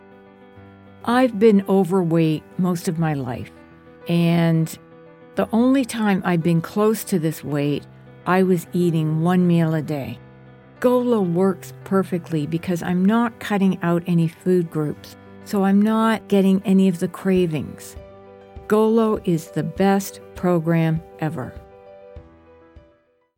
Female
English (North American)
Adult (30-50), Older Sound (50+)
Radio / TV Imaging
Engaging, Grounded, Real